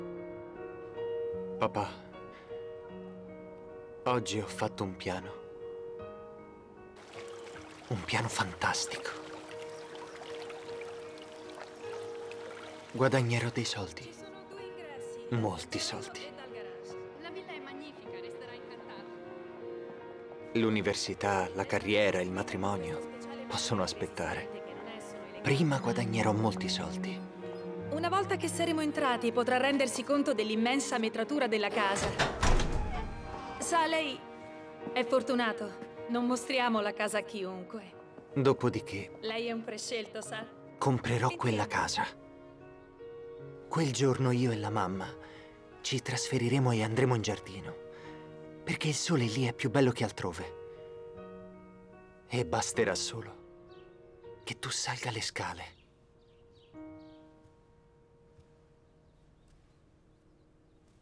nel film "Parasite", in cui doppia Woo-sik Choi.